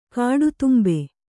♪ kāḍu tumbe